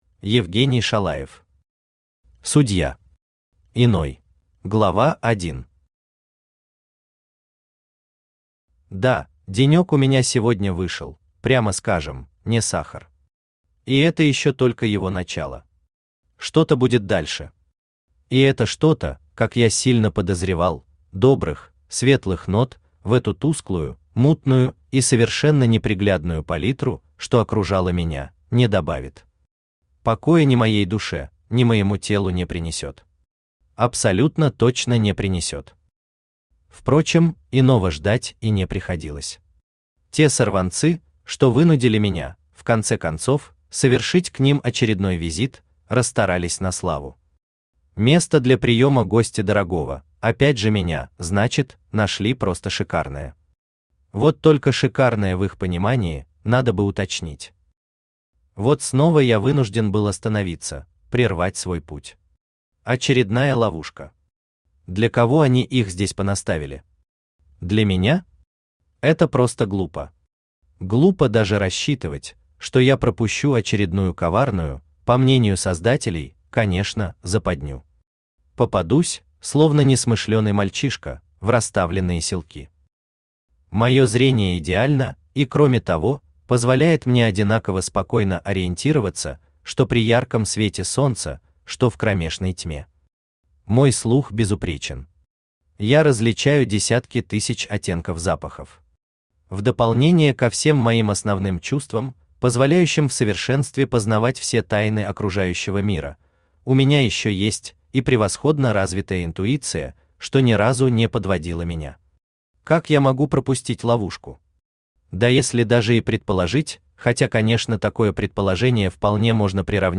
Аудиокнига Судья. Иной | Библиотека аудиокниг
Иной Автор Евгений Викторович Шалаев Читает аудиокнигу Авточтец ЛитРес.